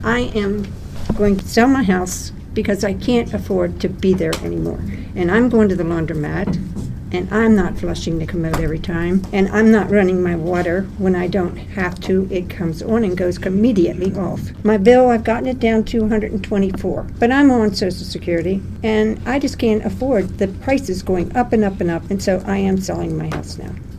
A full house at the Cresaptown Volunteer Fire Department last night participated in a special press conference held for residents of Bel Air, Glen Oaks, Pinto, and Highland Estates to share their frustration with increasingly high water bills from the Maryland Water Service.